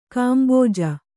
♪ kāmbōja